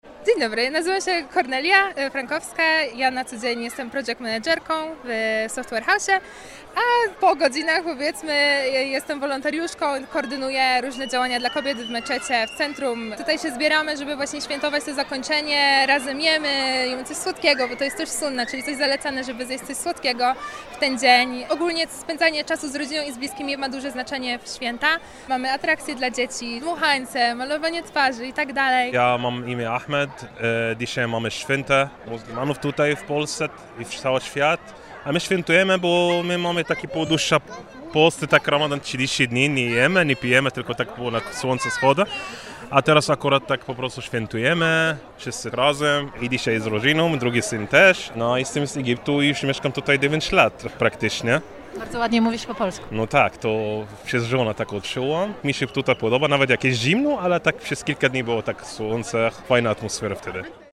Święto Eid odbyło się po raz pierwszy nie na Kasprowicza, lecz w wynajętej przestrzeni Stadionu Olimpijskiego we Wrocławiu. Spytaliśmy uczestników, jak świętują ten czas.
muzulmanie-relacja.mp3